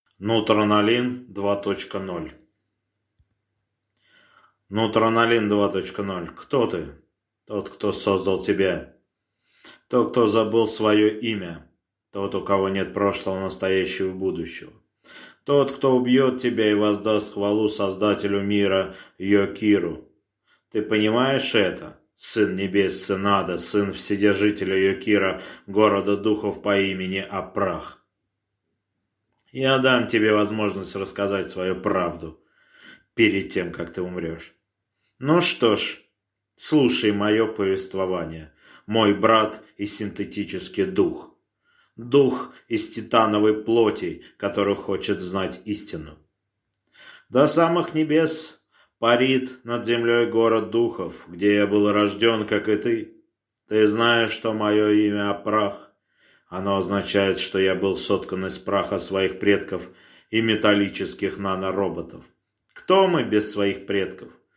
Аудиокнига Нутронолин 2.0 | Библиотека аудиокниг